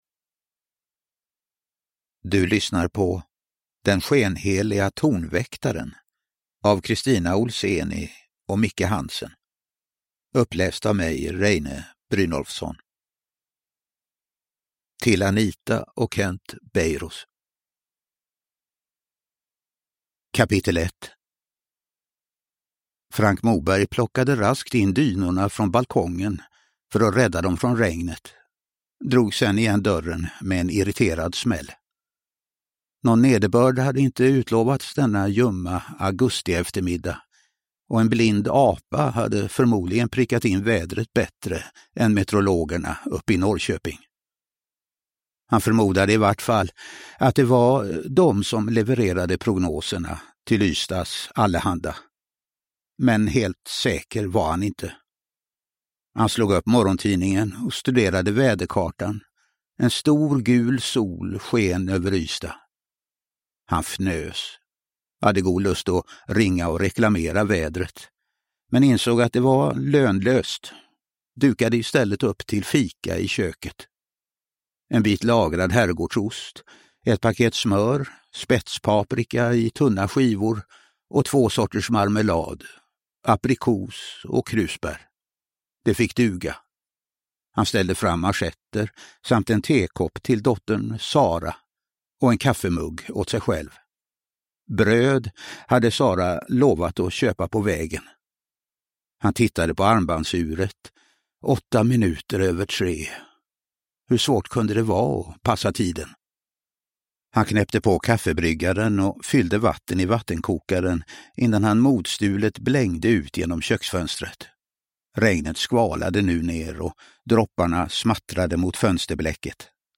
Uppläsare: Reine Brynolfsson
Ljudbok